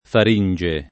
far&nJe] s. f. — meno com. l’uso come s. m., freq. peraltro presso i medici (e più freq. nei comp. come rinofaringe) — femm. o masch. il gr. φάρυγξ / phárynx; masch. il fr. pharynx